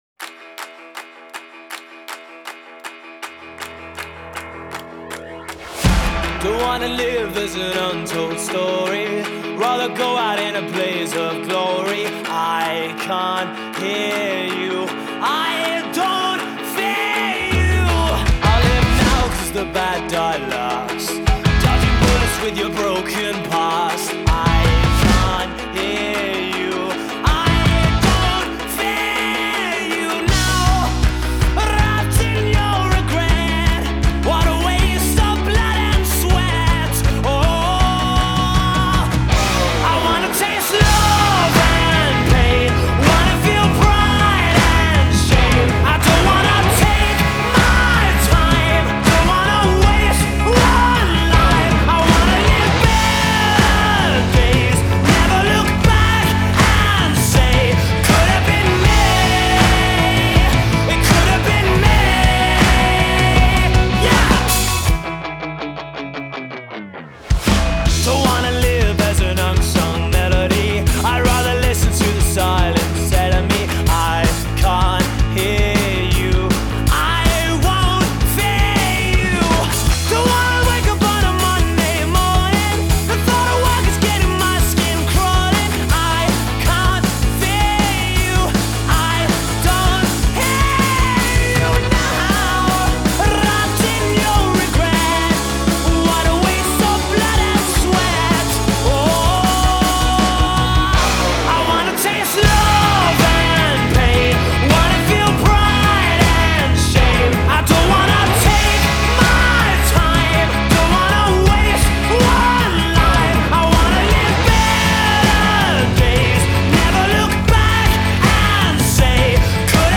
Genre: Rock, Indie Rock